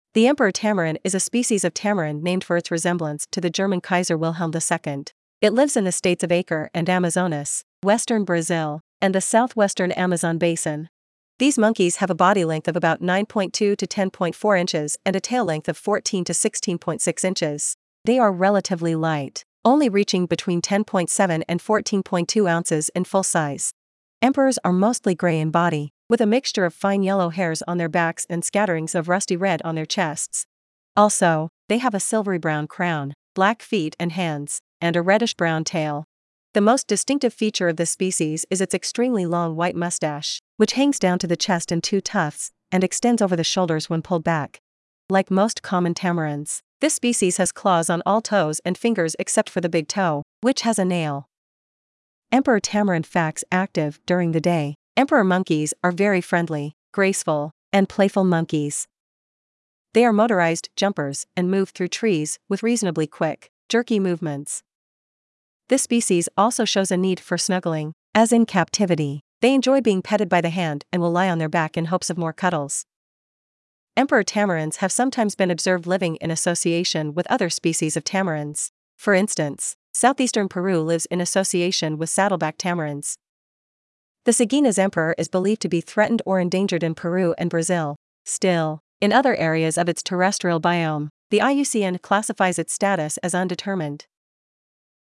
Emperor Tamarin
Emperor-Tamarin.mp3